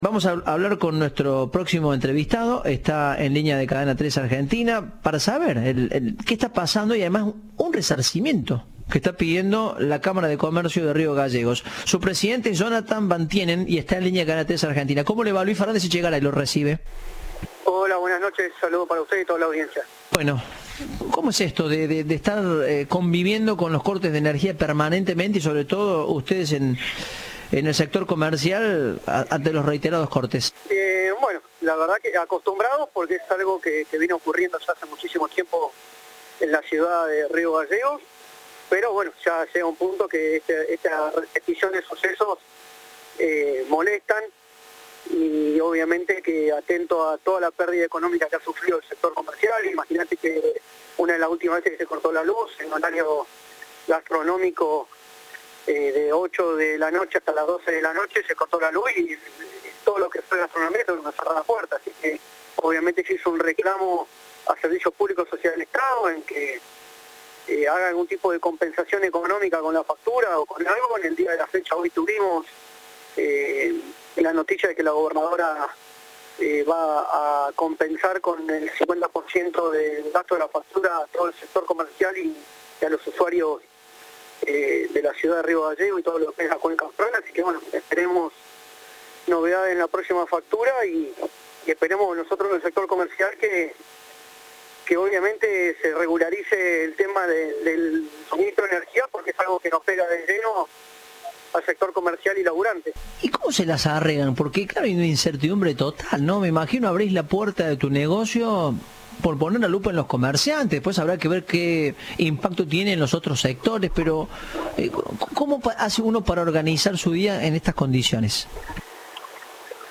Entrevista de "Informados, al regreso".